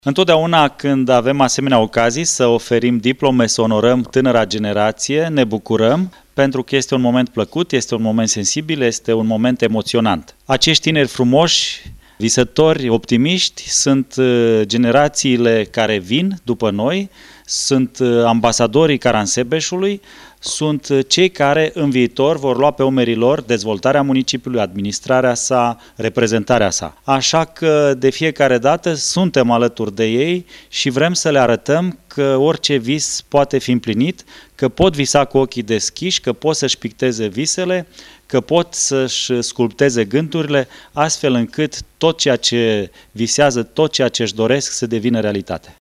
Devenită deja tradiţie, manifestarea a avut loc în sala de festivităţi a Primariei Caransebeş,unde tinerii au primit flori şi un certificat care atestă calitatea de cetăţeni majori cu drepturi şi obligaţii ai municipiului.
La finalul evenimentului, primarul Marcel Vela a declarat: